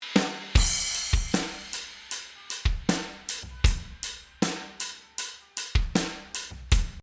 For example, here’s a full drum kit, around five microphones grouped to a submix with some compression on it. It’s completely dry except for the small amount of room sound.
drumsdry.mp3